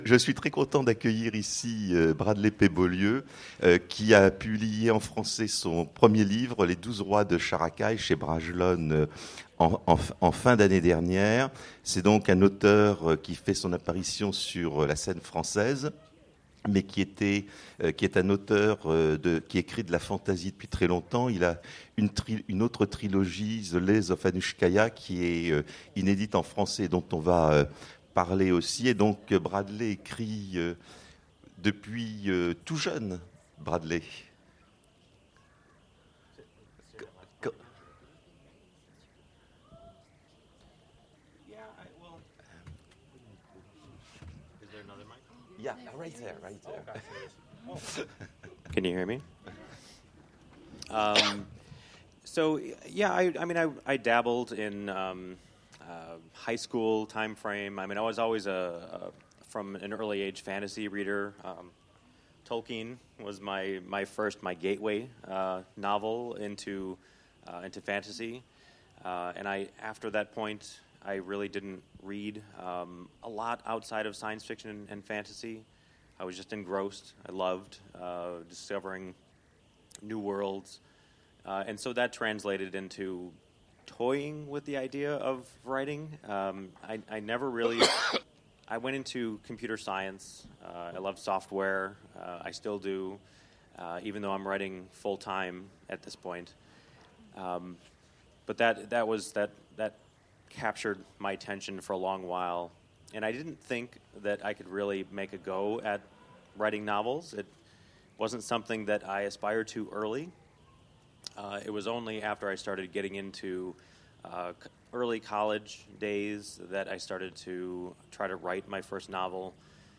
Imaginales 2017
Mots-clés Rencontre avec un auteur Conférence Partager cet article